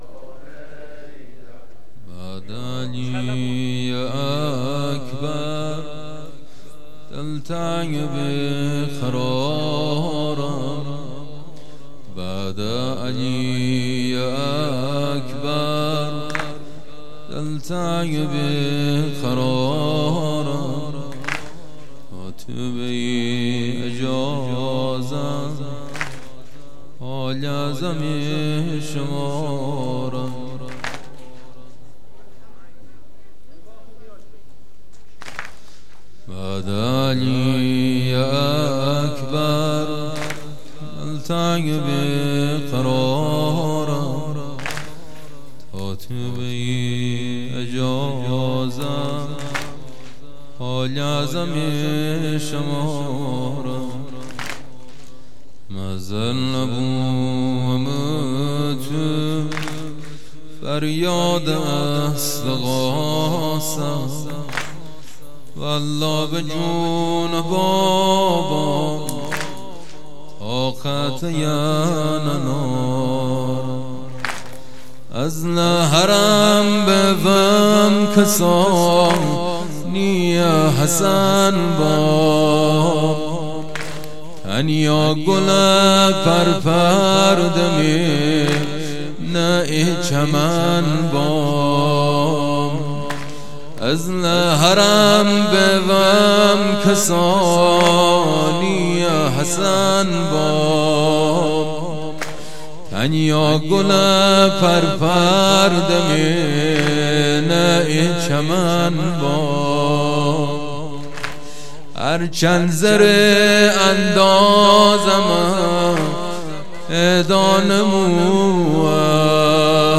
خیمه گاه - بوتراب علیـہ السلام - گِرد حرم جامه (دور لری)
محرم ۱۴۰۱